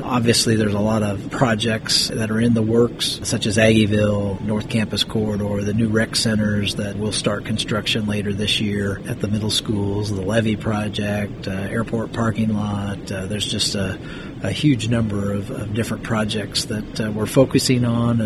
Here, Fehr describes some of the projects the city is currently working on.